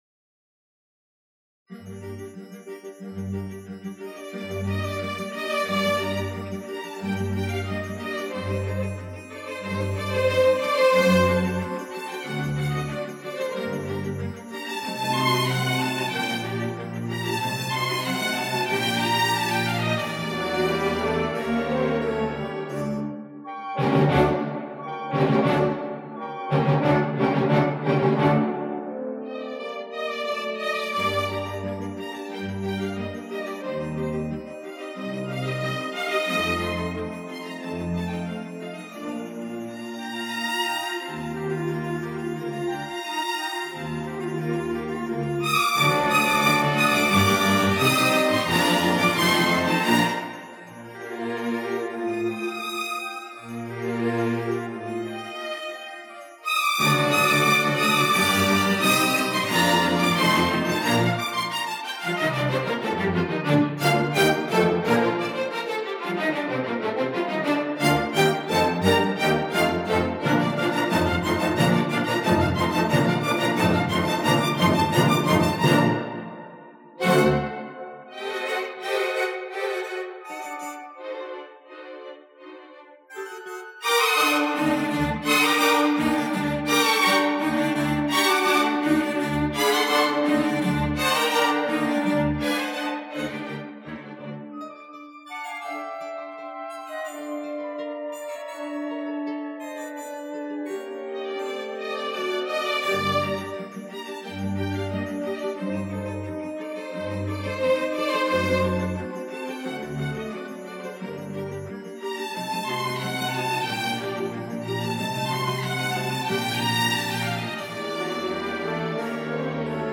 Формат:Vinyl, LP, Stereo
Жанр:Jazz, Pop, Classical, Stage & Screen
Стиль:Easy Listening, Theme